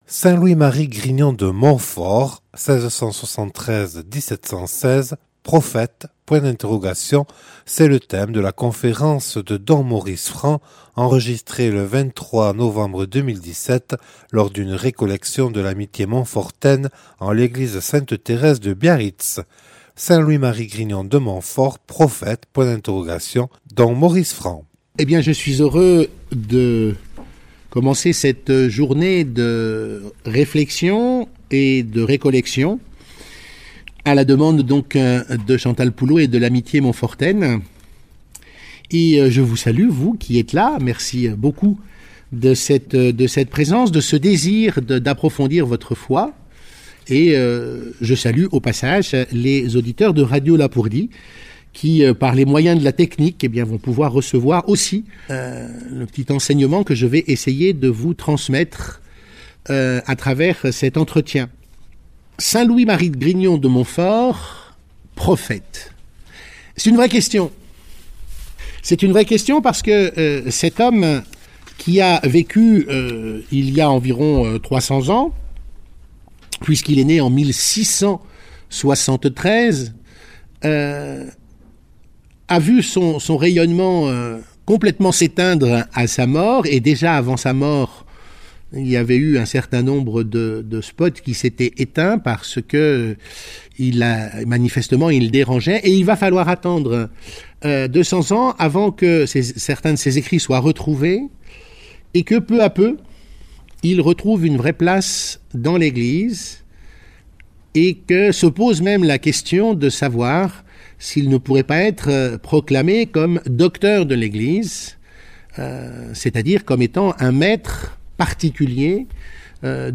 Enregistré le 23/11/2017 lors d'une récollection de l'Amitié Montfortaine à l'église sainte Thérèse de Biarritz